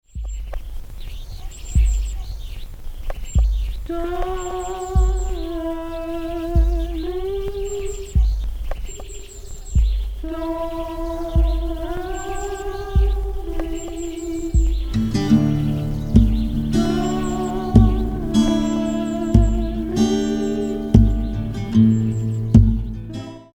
The acoustic guitar chimes in
Birds Chirping